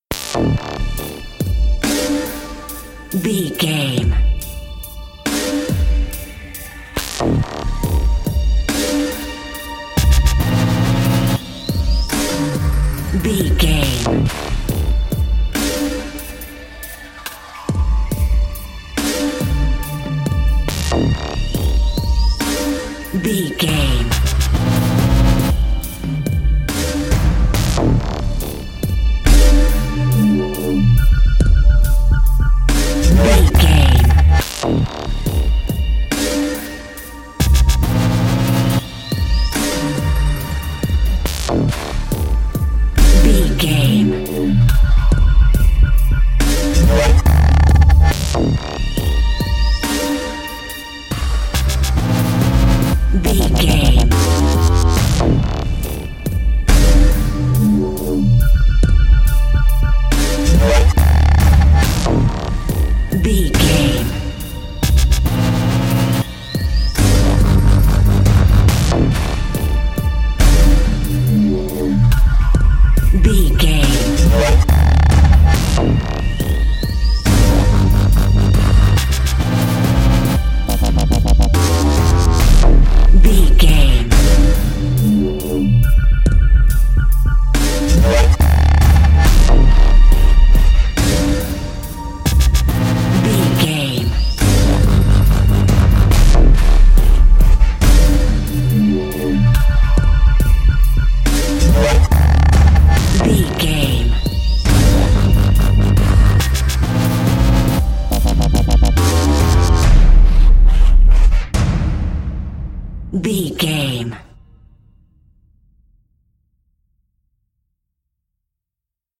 Aeolian/Minor
D
synthesiser
drum machine
orchestral hybrid
dubstep
aggressive
energetic
intense
strings
synth effects
wobbles
driving drum beat
epic